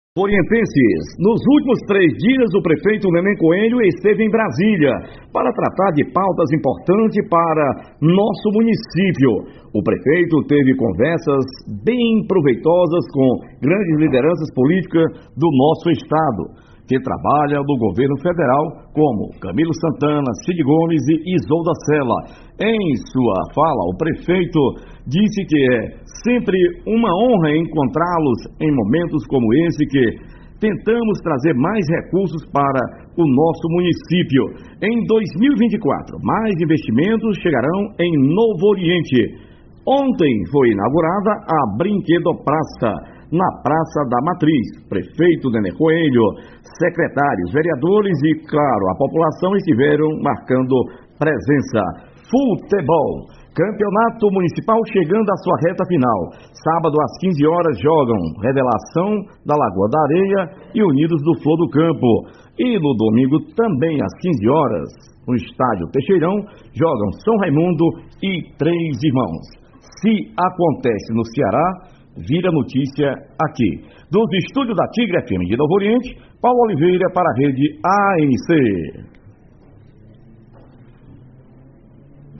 Nosso correspondente trás também informações sobre lazer e futebol.